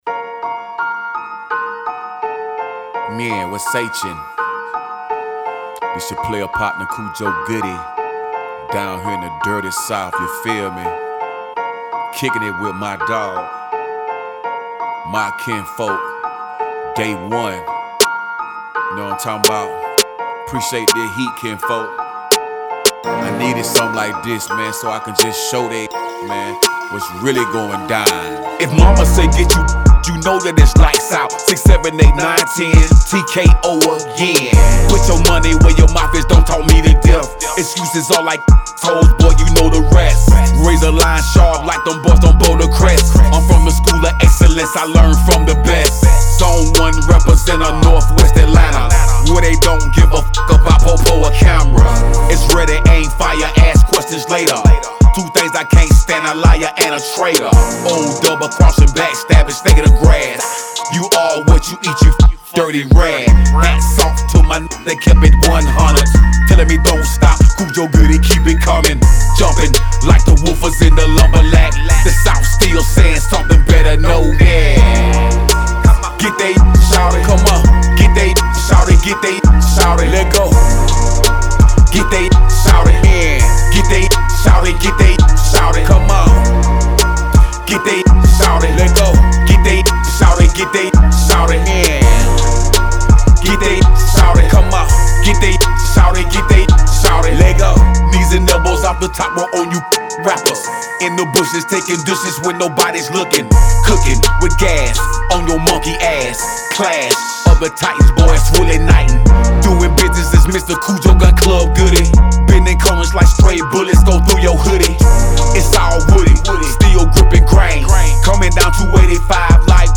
Genre: Rap RAP.